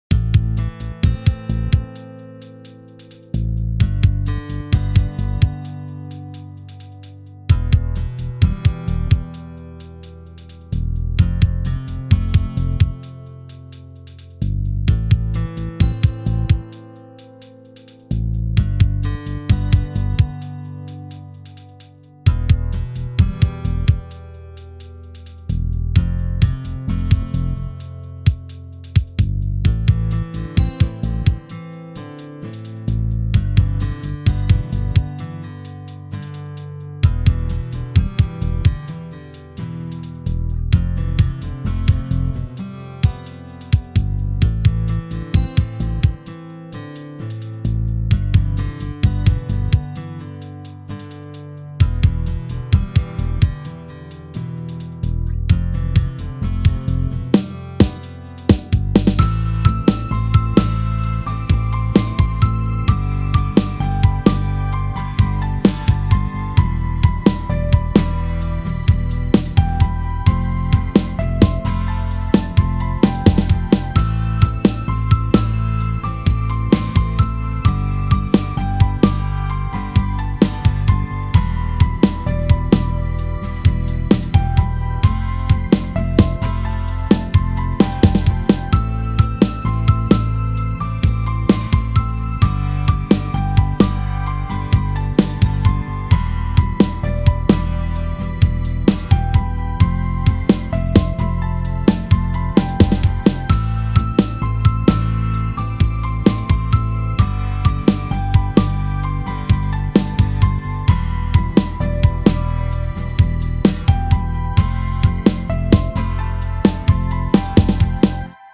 В общем фишка такая, написал трек по отдельности все инструменты играют нормально, а когда все вместе получается белиберда какая та т.е. сами все инструменты слышны, но некоторые приглушают другие. Допустим в треке есть гитарная партия при включении бочки или снейра...